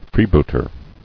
[free·boot·er]